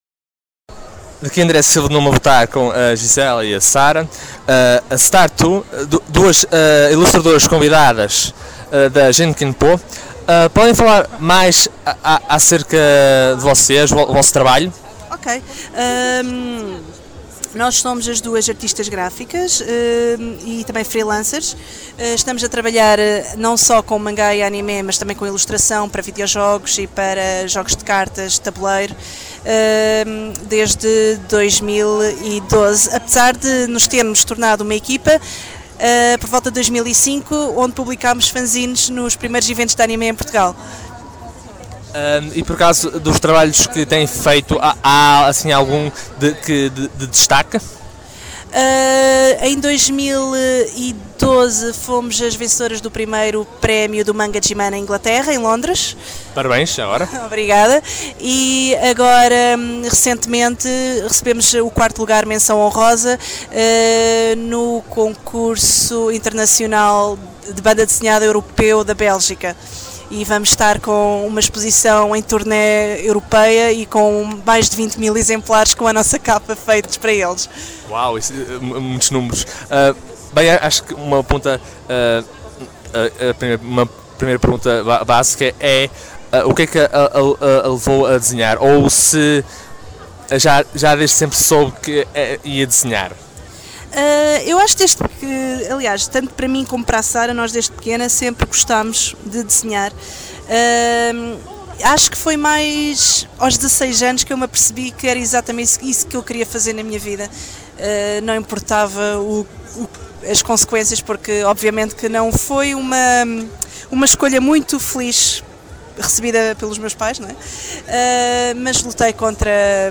Também neste episódio incluímos a nossas entrevistas feitas durante o Iberanime: